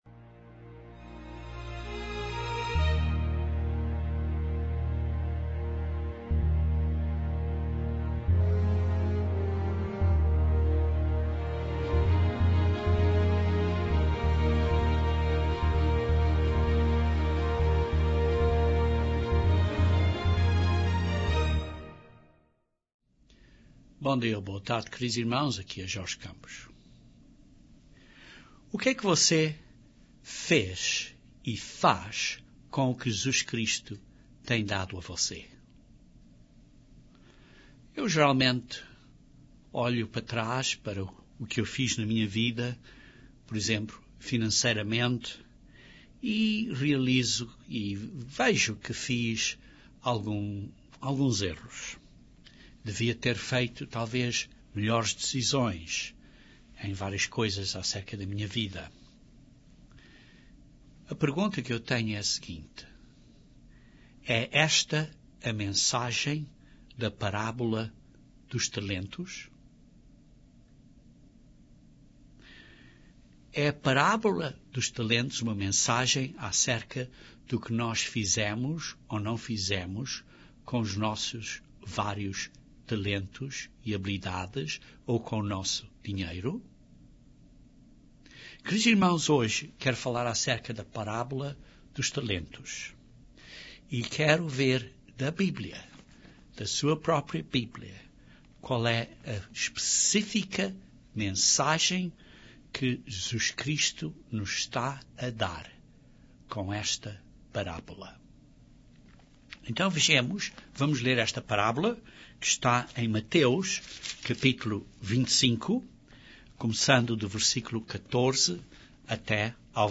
Este sermão aborda cuidadosamente e detalhadamente a parábola dos talentos.